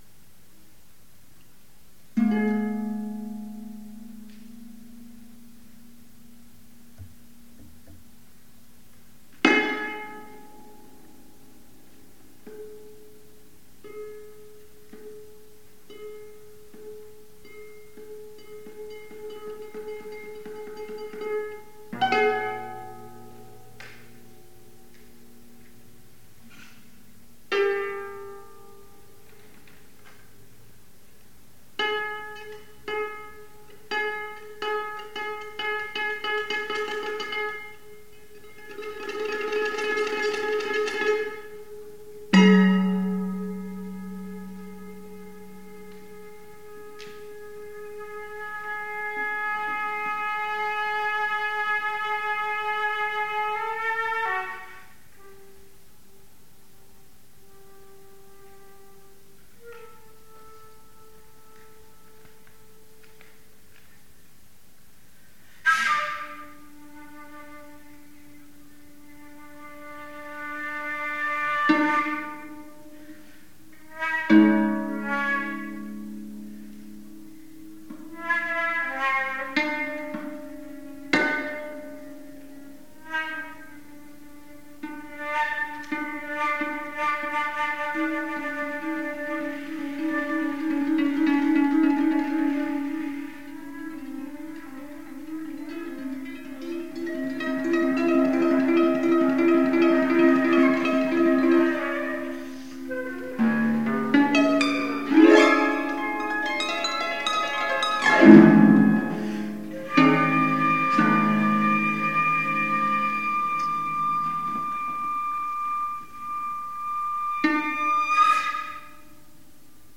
for Flute and Harp
Flute
Harp